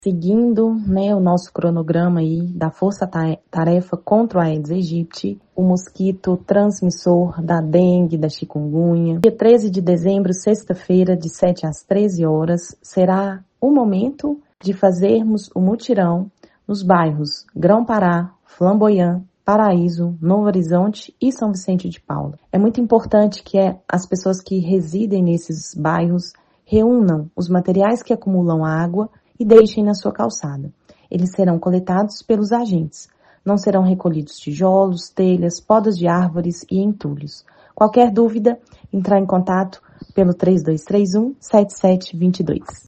A secretária municipal de Saúde, Ana Clara Teles Meytre, reforça o pedido de colaboração aos moradores dos bairros Grão-Pará, Flamboyant, Paraíso, Novo Horizonte e Conjunto Habitacional São Vicente de Paula, na sexta-feira, 13 de dezembro: